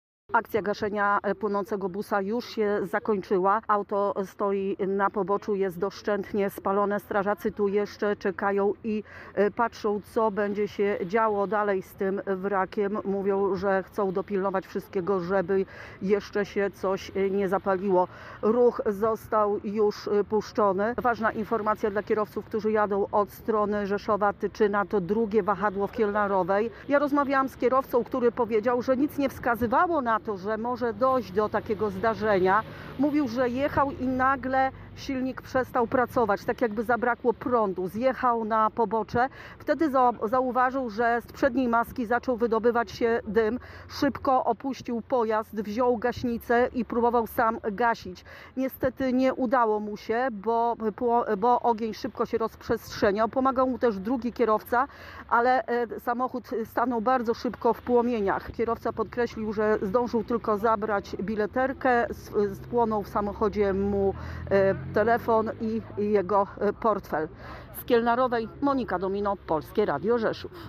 Relacja kierowcy busa